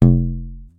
PVC_pipe_hit_1
block bong bonk bottle bumper container drop dropped sound effect free sound royalty free Music